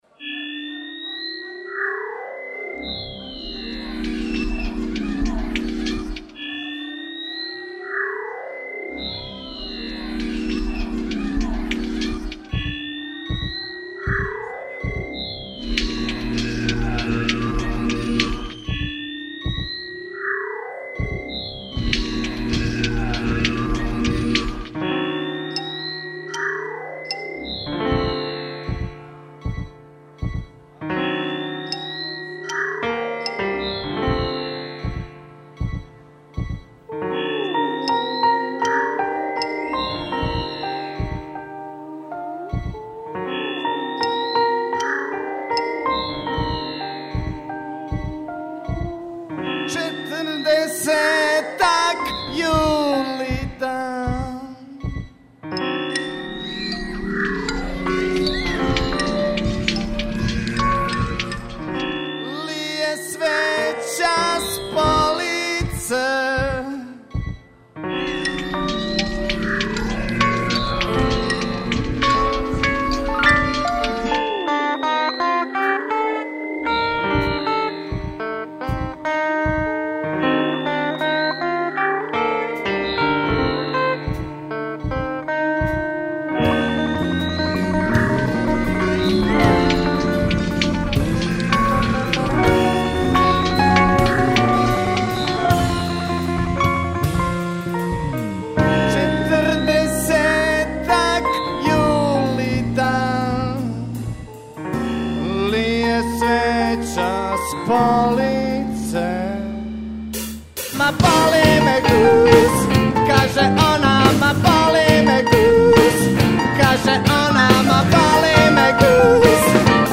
Bas gitaru